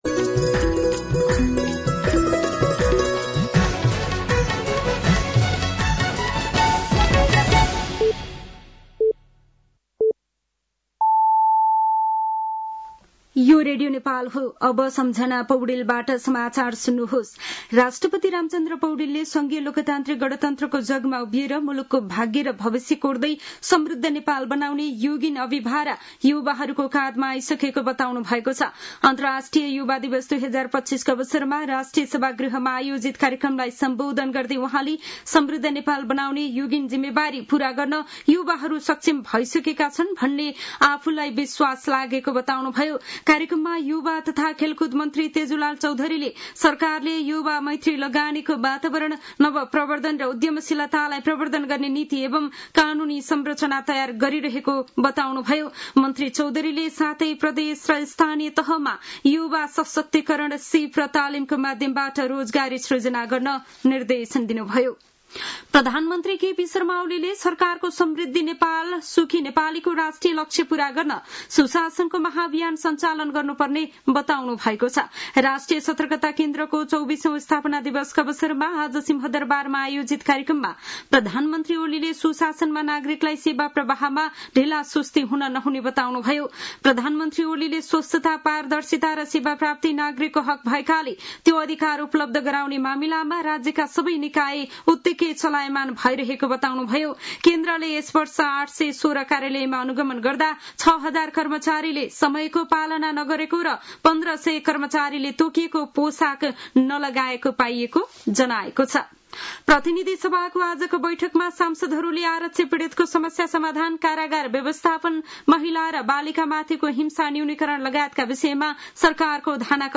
साँझ ५ बजेको नेपाली समाचार : २७ साउन , २०८२
5-pm-news-4-27.mp3